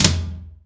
ROOM TOM4B.wav